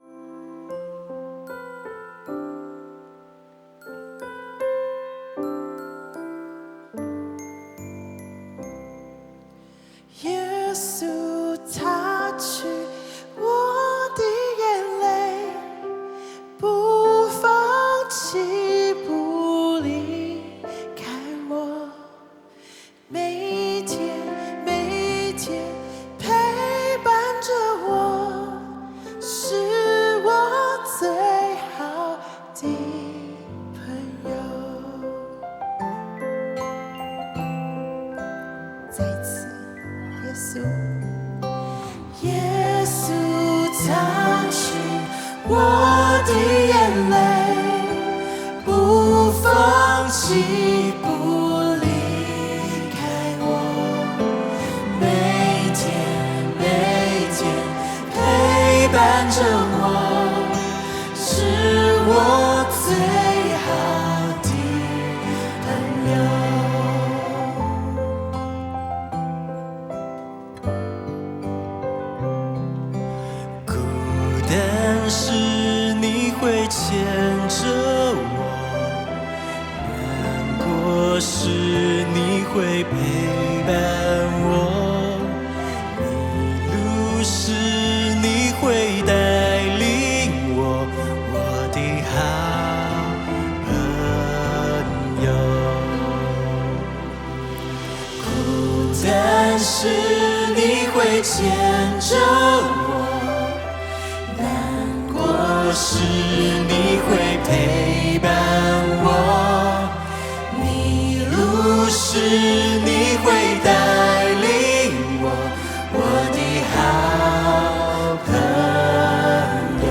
以原声（Acoustic）的方式呈现